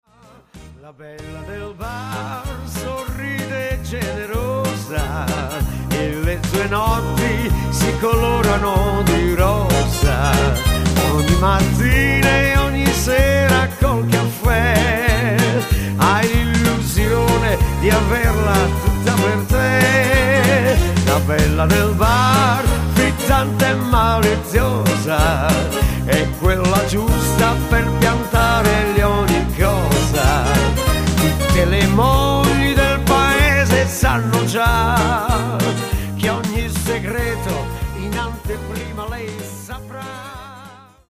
FOX MODERATO  (3.14)